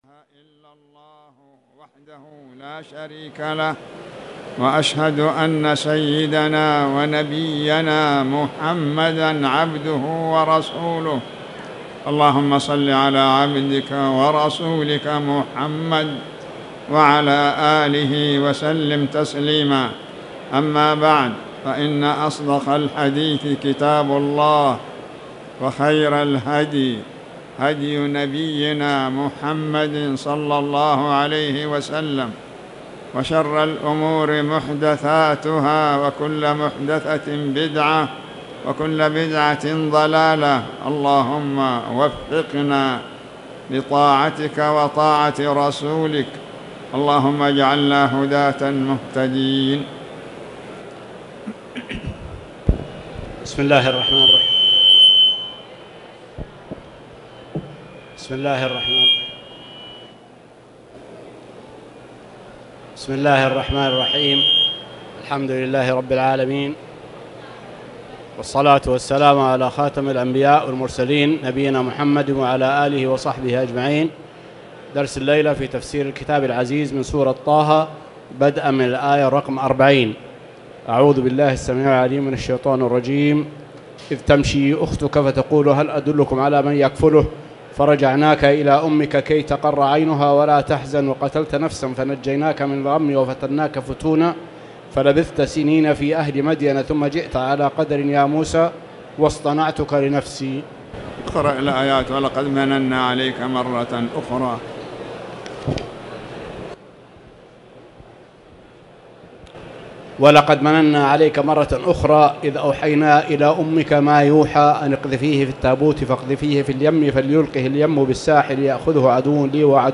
تاريخ النشر ١٤ جمادى الآخرة ١٤٣٨ هـ المكان: المسجد الحرام الشيخ